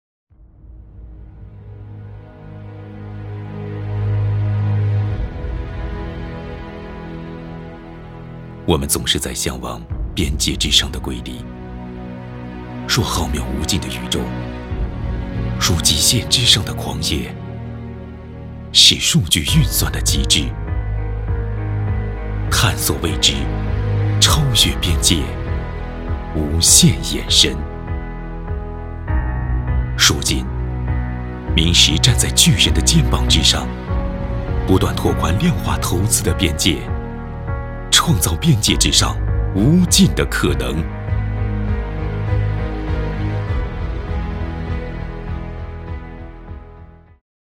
淘声配音网，专题，宣传片配音，专业网络配音平台 - 淘声配音网配音师男国语203号 大气 沉稳 厚重
配音风格： 大气 沉稳 厚重